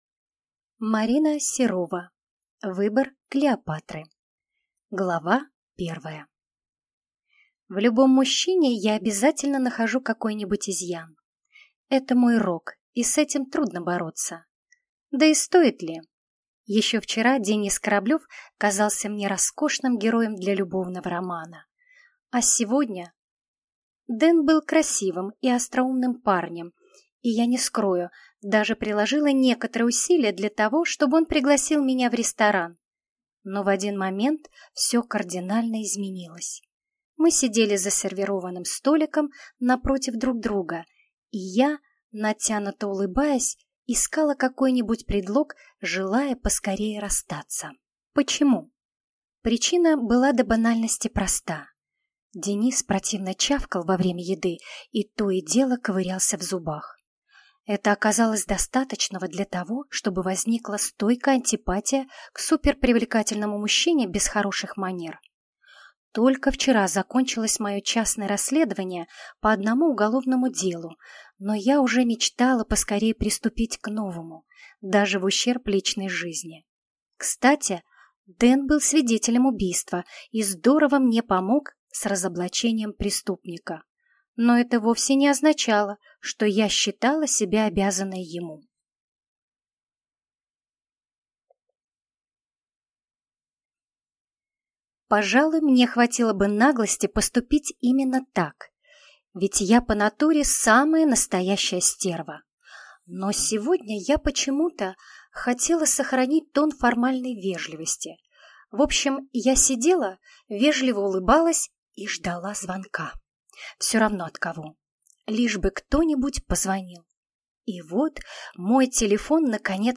Аудиокнига Выбор Клеопатры | Библиотека аудиокниг